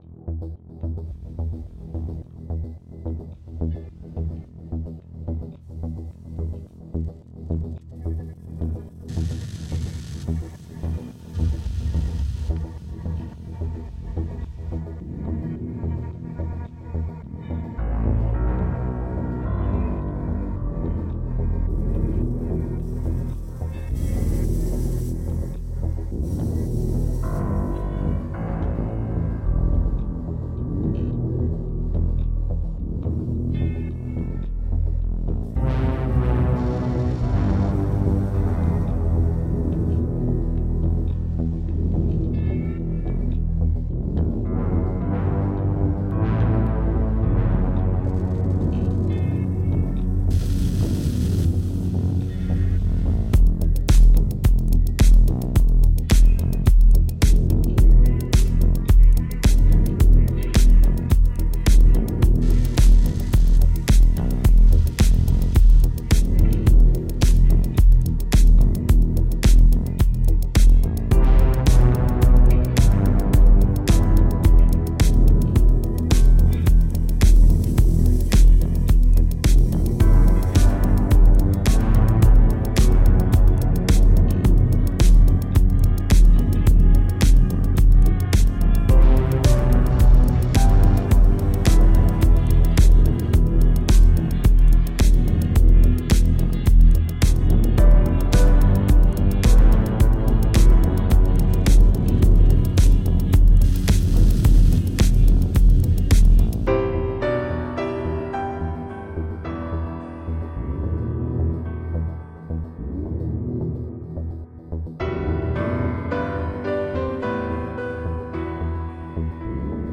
Industrial acid house..